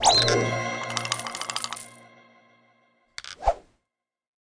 Mode Decolorization Sound Effect
Download a high-quality mode decolorization sound effect.